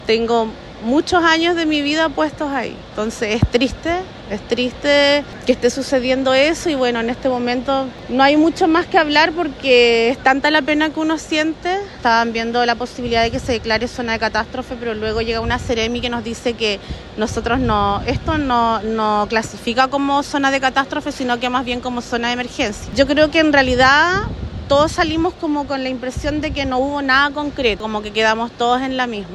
Radio Bío Bío en la zona conversó con una de las emprendedoras afectadas, quien lamentó la pérdida de “los años de su vida puestos ahí”.